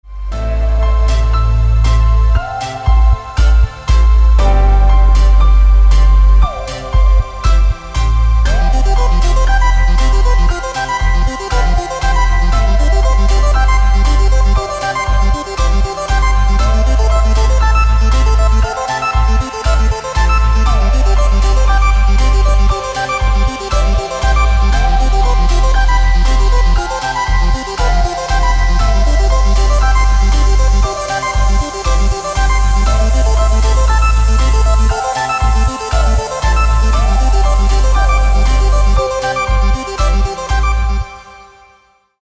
• Качество: 320, Stereo
атмосферные
электронная музыка
спокойные
без слов
Electronica
Downtempo
клавишные